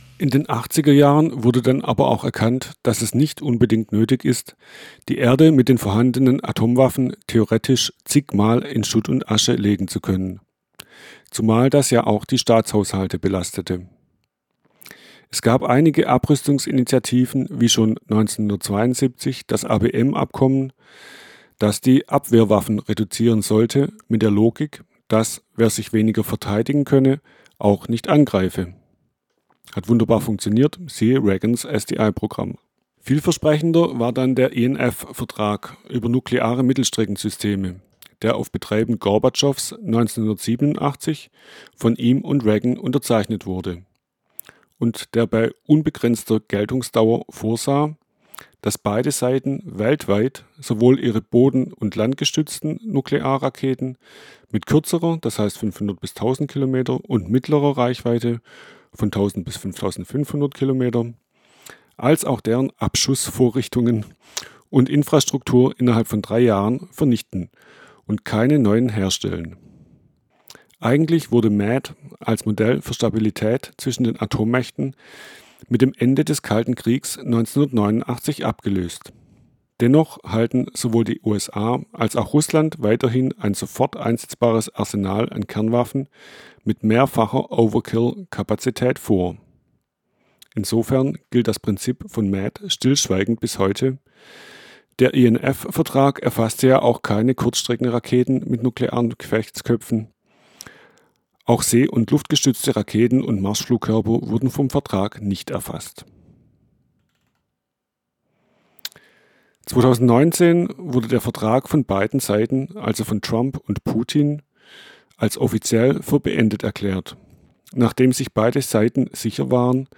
Text-to-Speech-Technologie von Piper und Thorsten-Voice